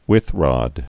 (wĭthrŏd, wĭth-, wīth-)